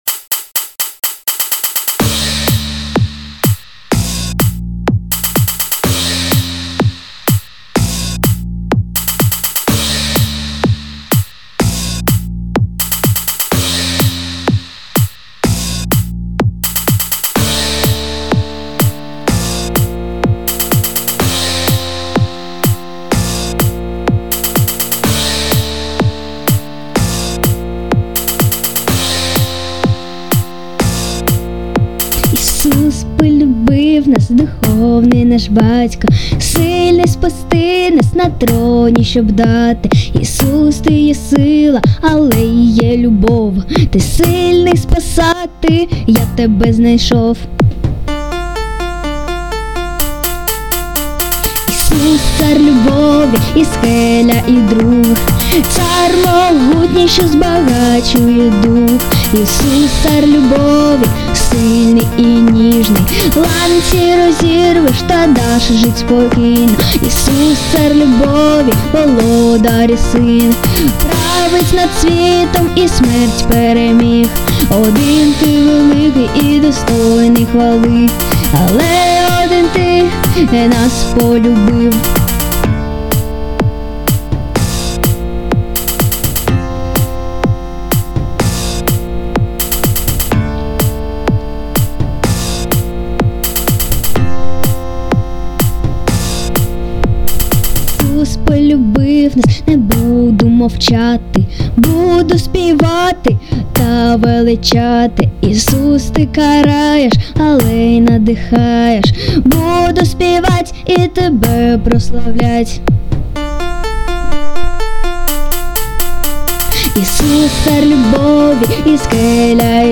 песня
49 просмотров 85 прослушиваний 0 скачиваний BPM: 125